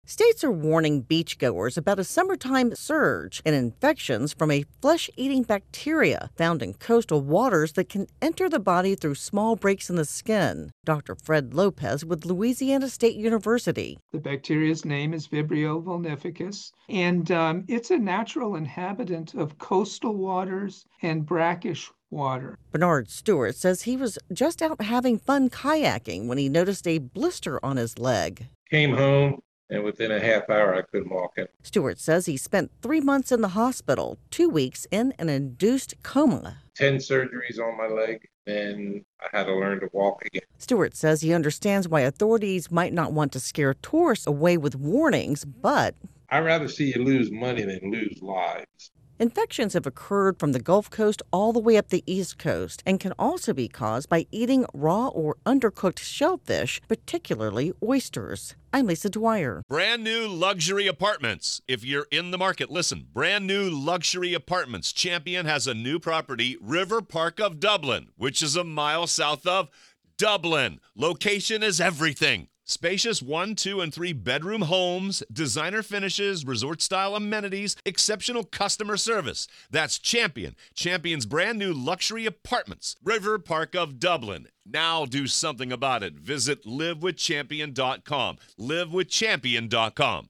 reports on an increase in flesh eating bacterial infections.